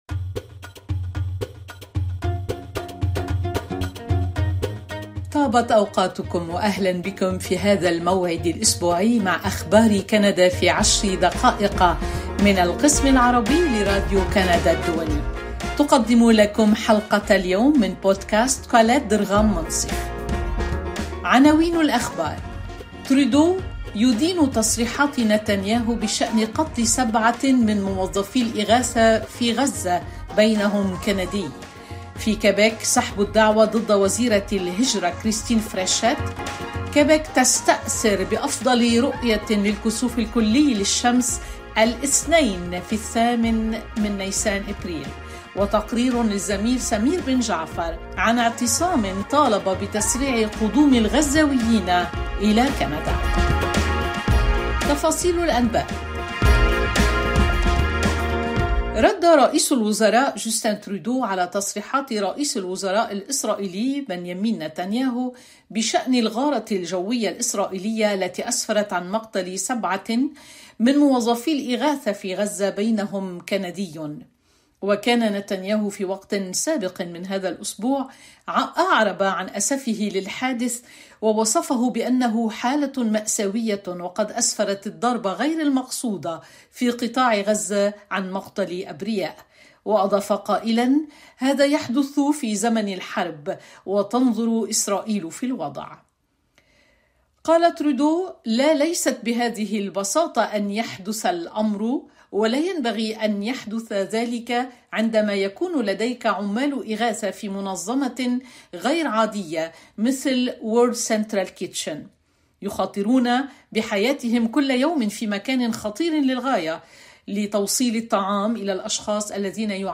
ترودو يدين تصريحاتِ نتنياهو بشأن قتل 7 من موظفي الإغاثة في غزة بينهم كندي، وفي كيبيك سحب الدعوى ضد وزيرة الهجرة كريستين فريشيت وكيبيك تستأثرُ بأفضل رؤيةٍٍ للكسوف الكلي للشمس الاثنين في الثامن من أبريل. تقرير